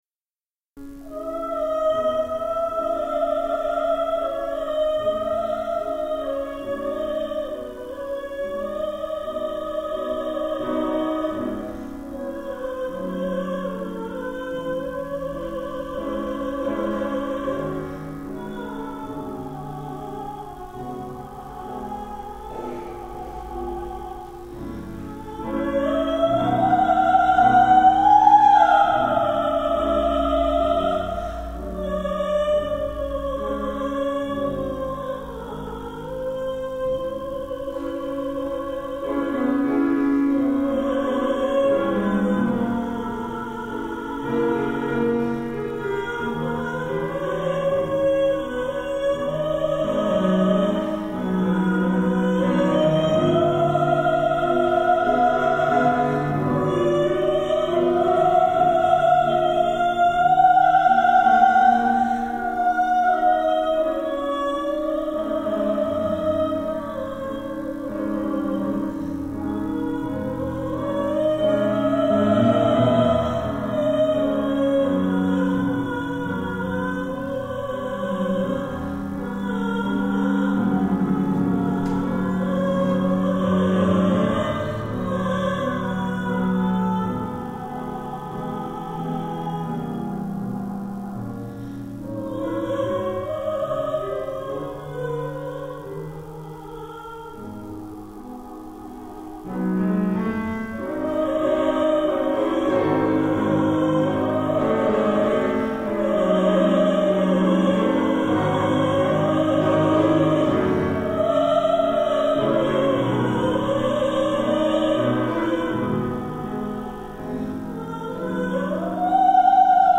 -Rachmaninoff's Vocalise (live in Bremen, Germany
soprano